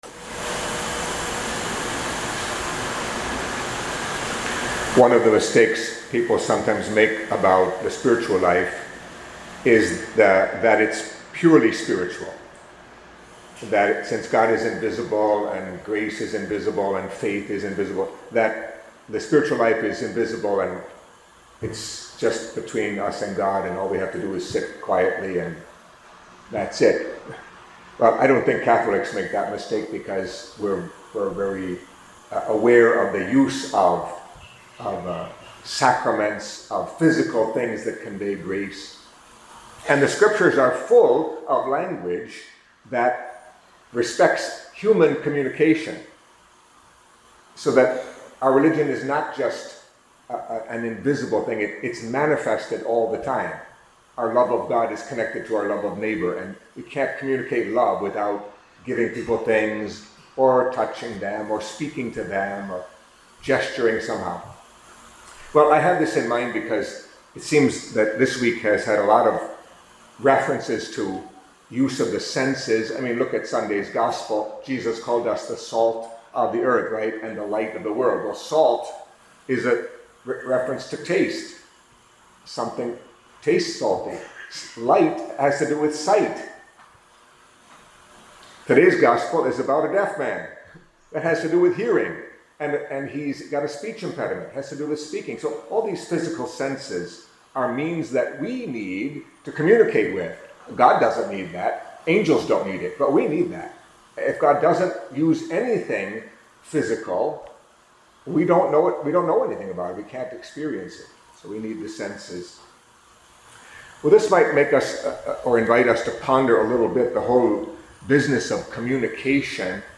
Catholic Mass homily for Friday of the Fifth Week in Ordinary Time